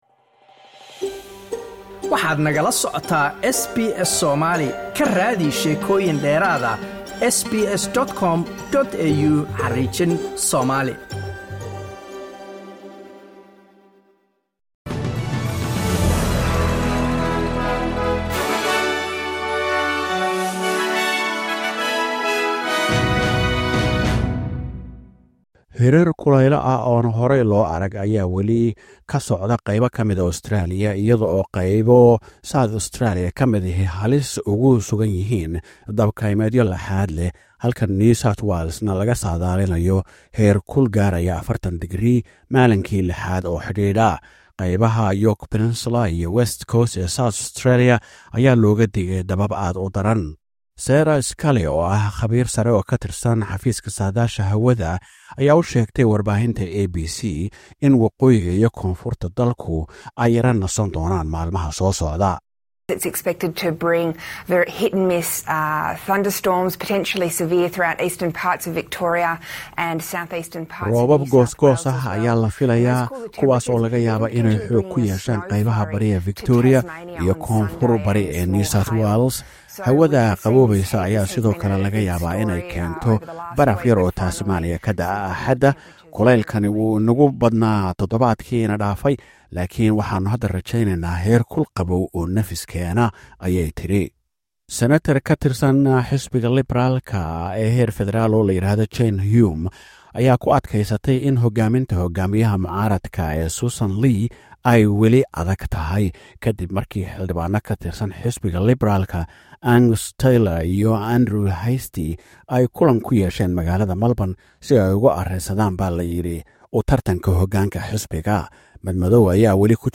SBS News Somali: Warka SBS ee Jimce 30 January 2026